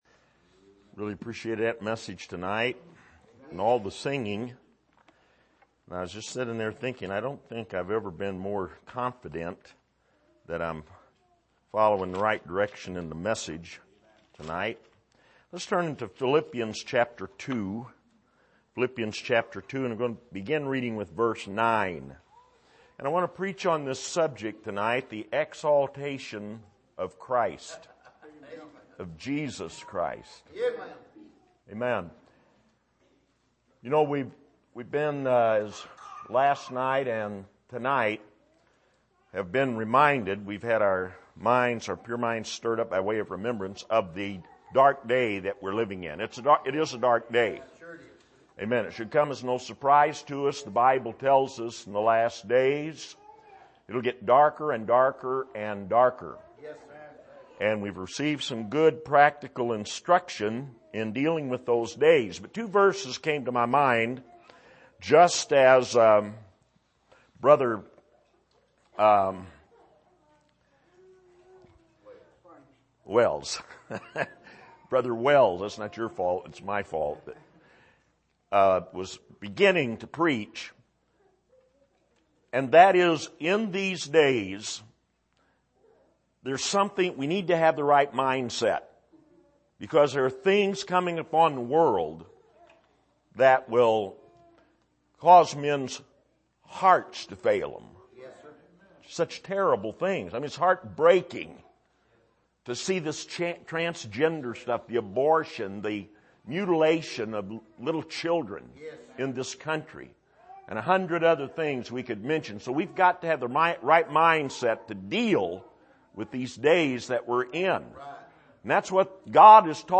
Service: Bible Conference